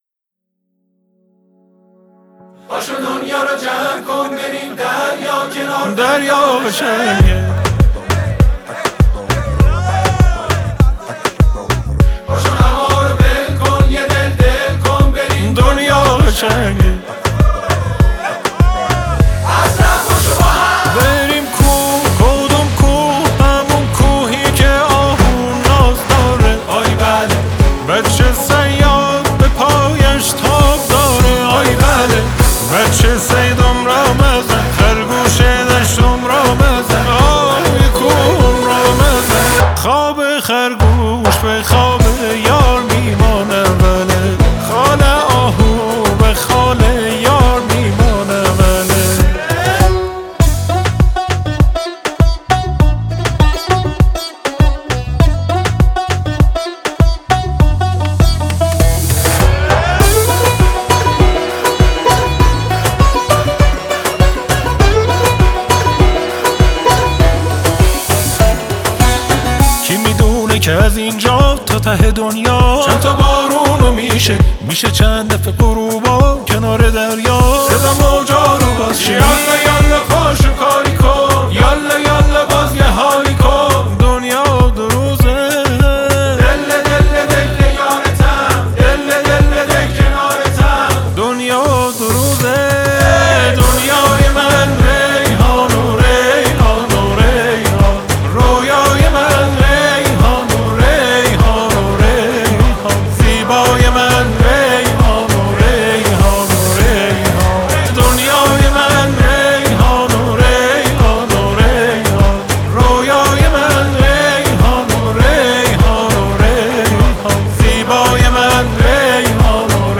پاپ
آهنگ شاد